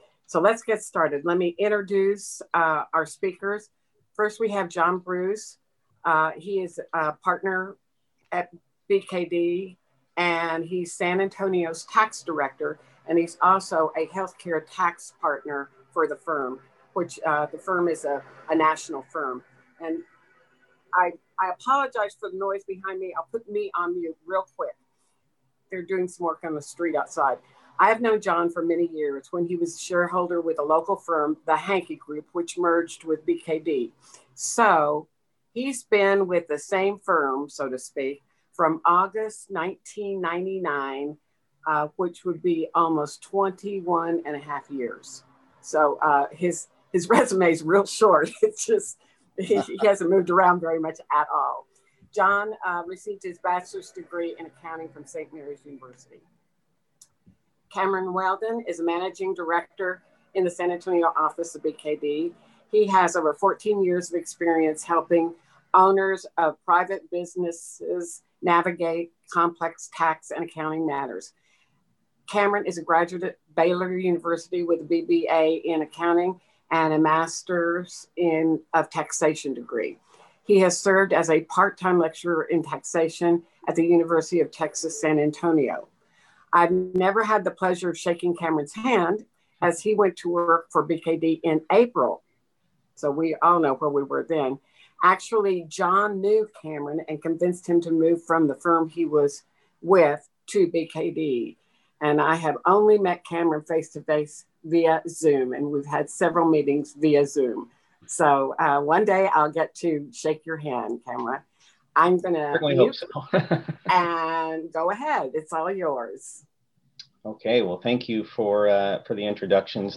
Online Conferences